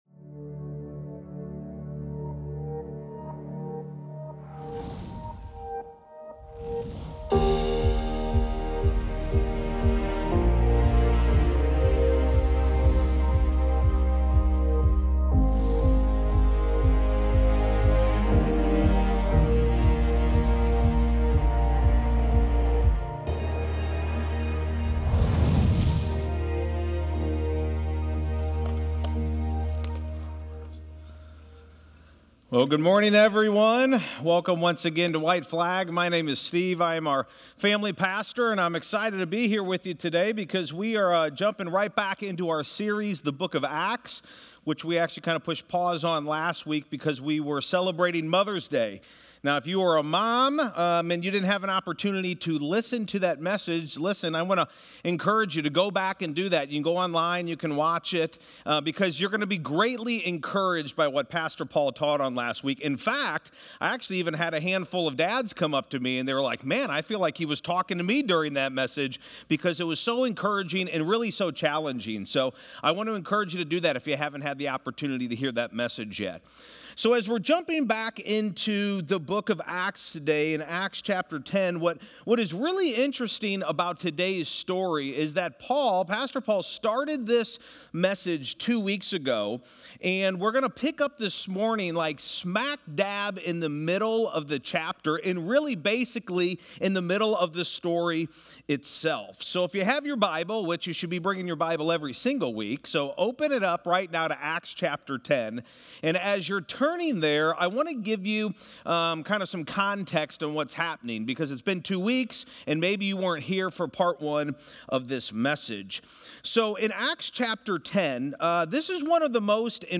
acts-5-18-sermon-audio.mp3